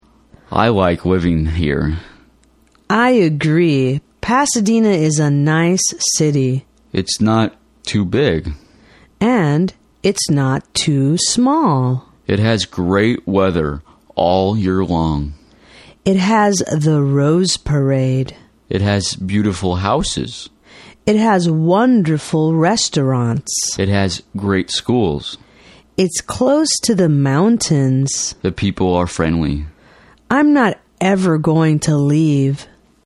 dialogue11.mp3